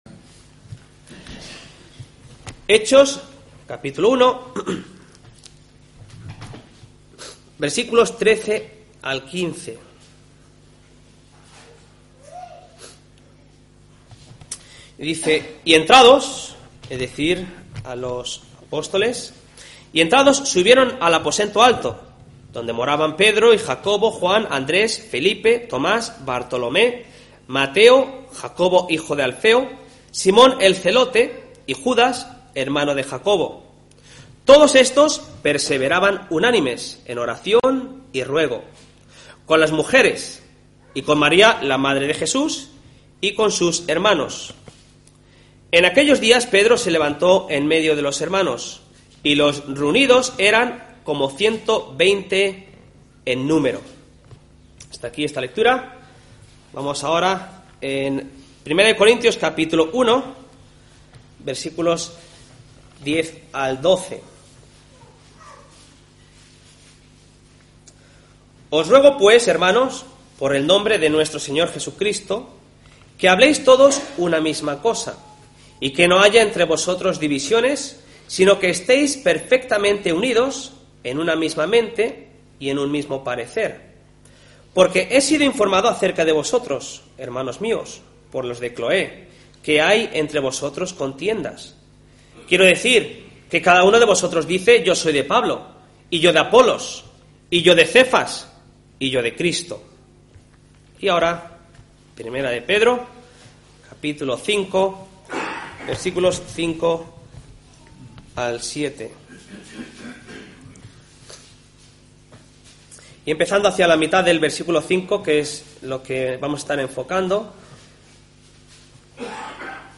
Listado Últimos Sermones